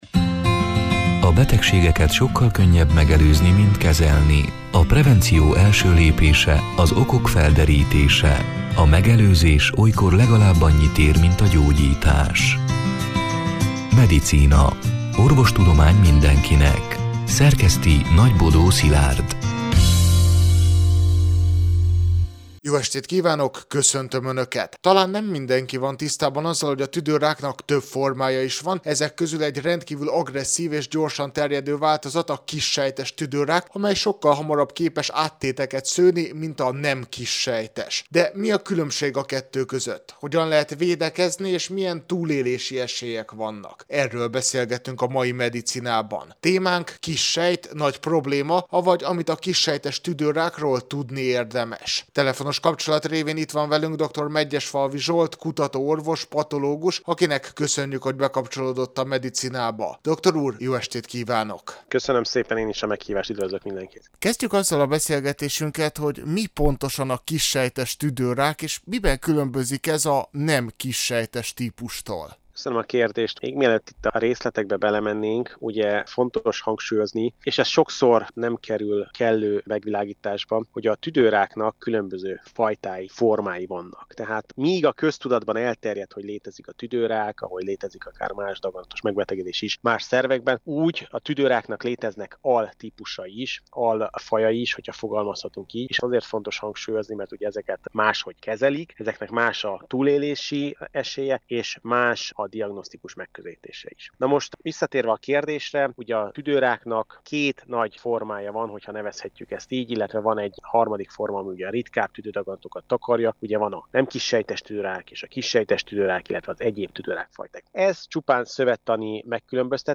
A Marosvásárhelyi Rádió Medicina (elhangzott: 2025. június 18-án, szerdán este nyolc órától) c. műsorának hanganyaga:
De mi a különbség a kettő között? Hogyan lehet védekezni és milyen túlélési esélyek vannak? Erről beszélgetünk a soron következő Medicinában!